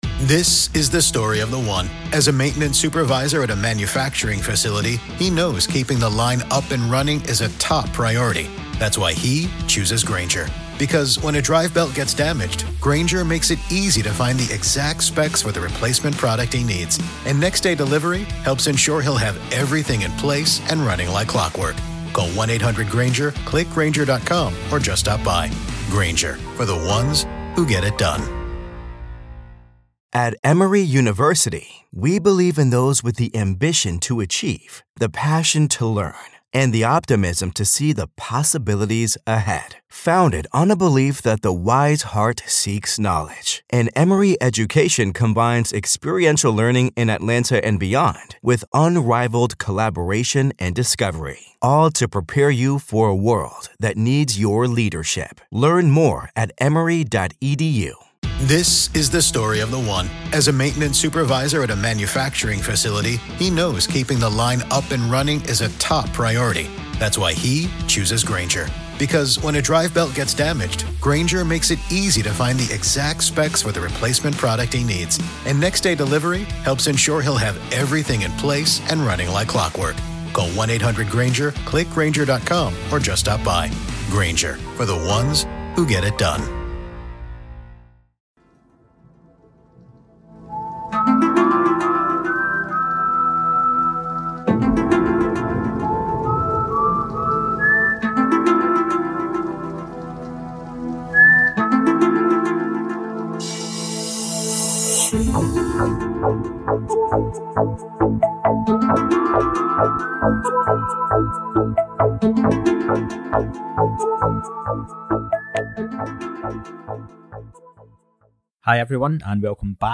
** Disclaimer; please note that there was bandwidth issues on Nicks end where his audio at times will distort, you can always hear what he is saying but appreciate your patience, i cleaned it up as best i could.